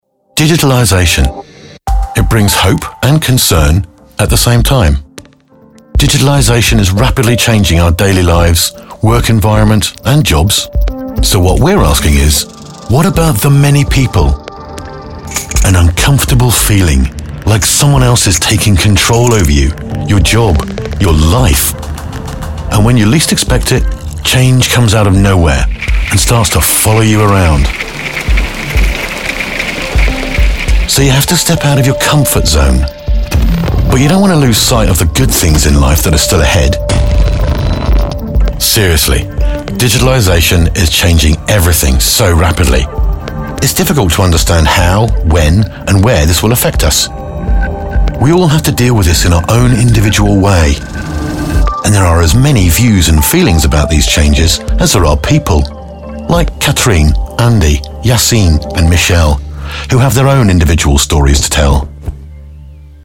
Profonde, Distinctive, Mature, Chaude, Corporative
Corporate